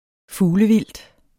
Udtale [ ˈfuːlə- ]